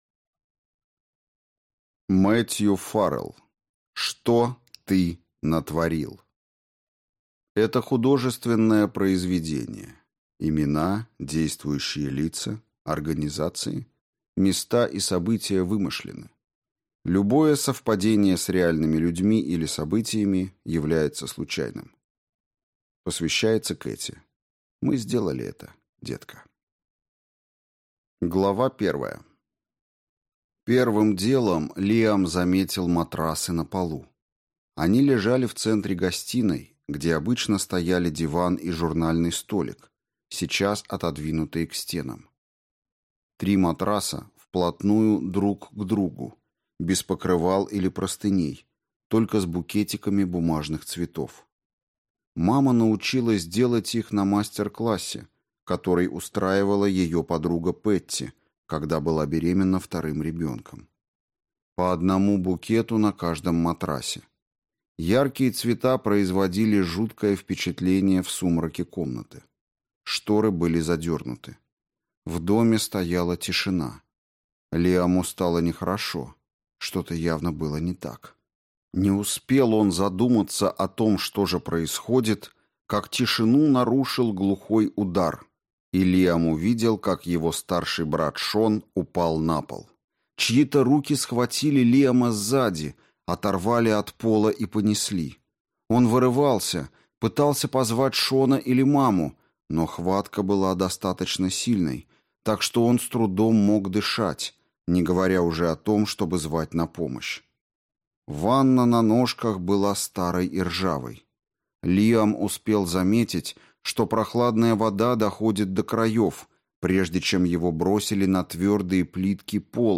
Аудиокнига Что ты натворил | Библиотека аудиокниг